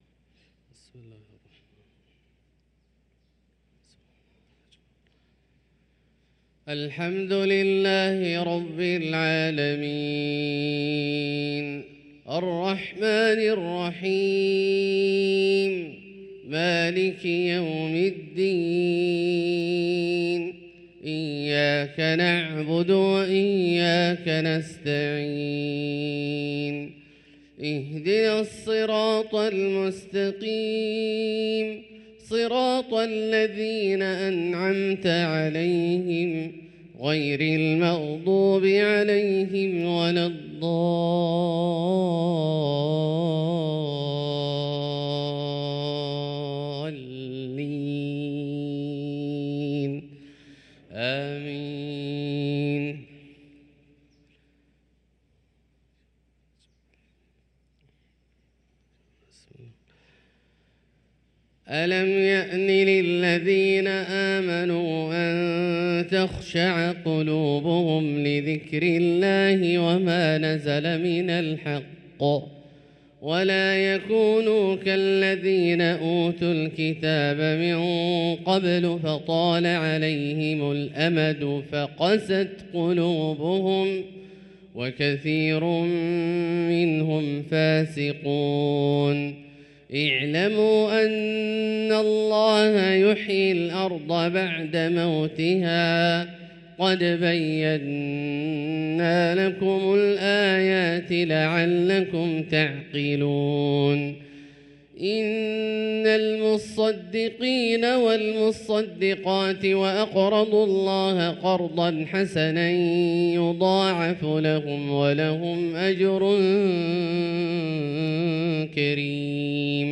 صلاة الفجر للقارئ عبدالله الجهني 9 ربيع الأول 1445 هـ
تِلَاوَات الْحَرَمَيْن .